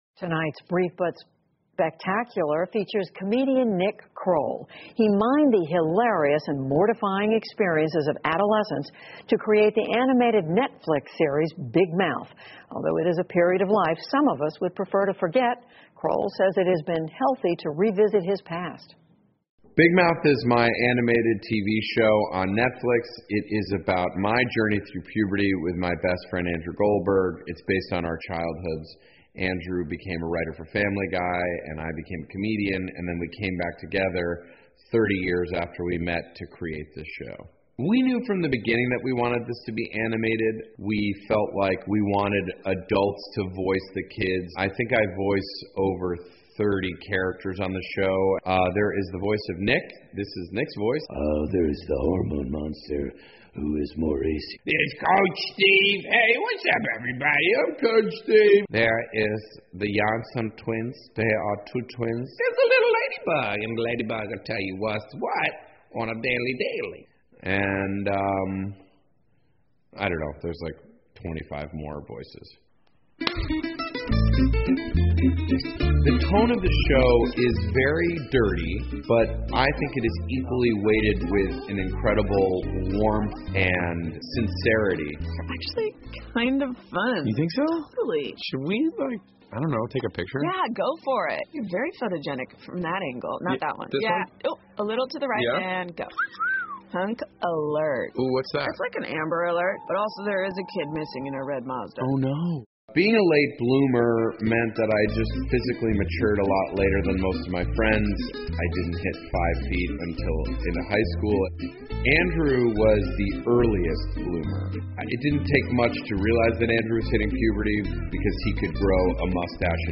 PBS高端访谈:喜剧演员尼克·克罗尔的《大嘴巴》 听力文件下载—在线英语听力室